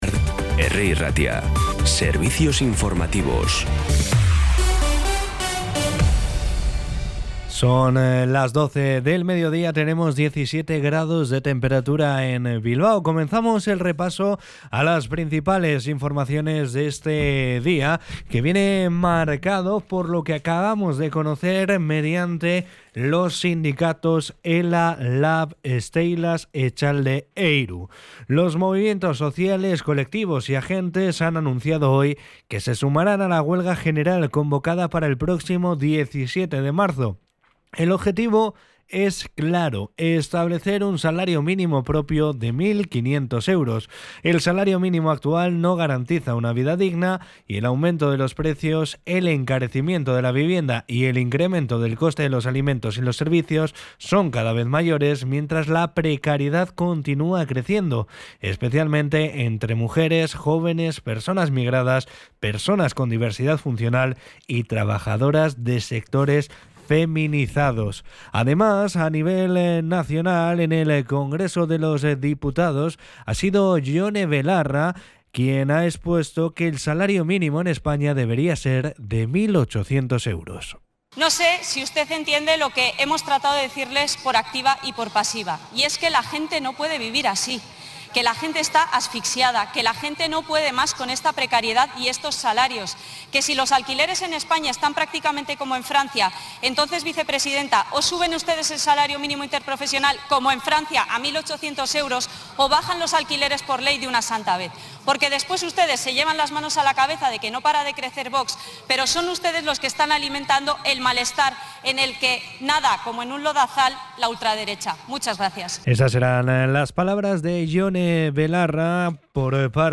Los titulares actualizados con las voces del día.